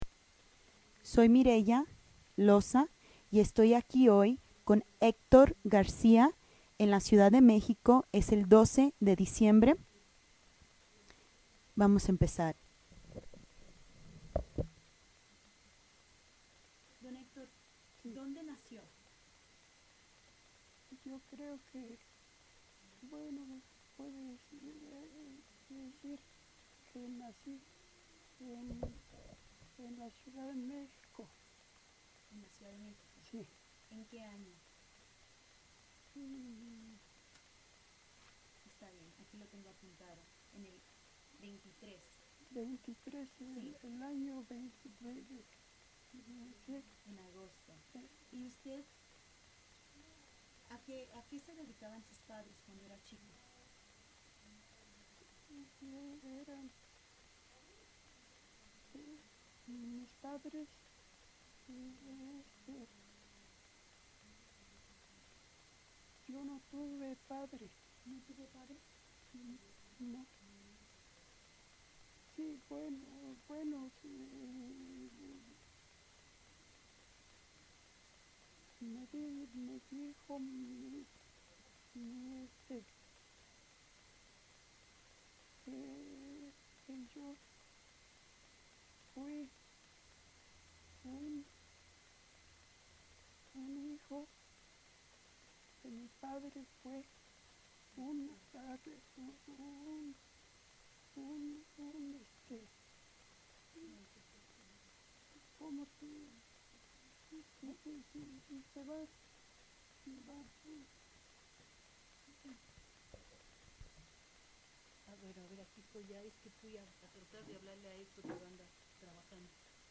Location Mexico City Original Format Mini disc